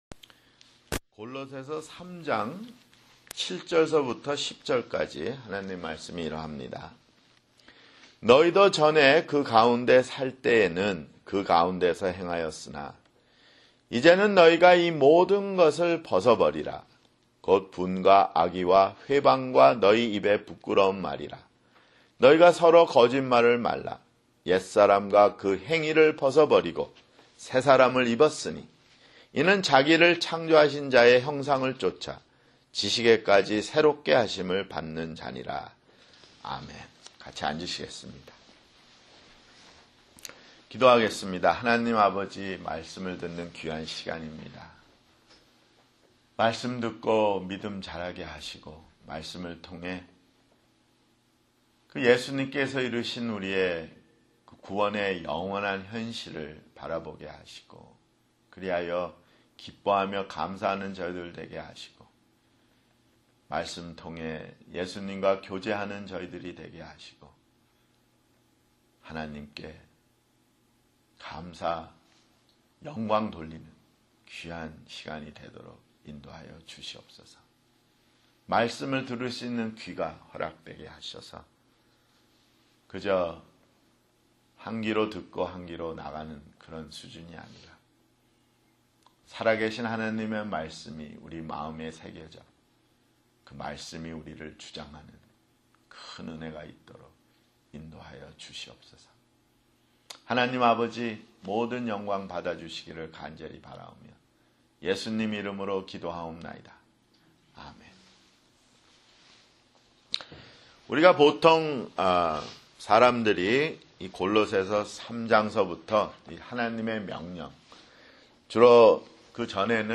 [주일설교] 골로새서 (60)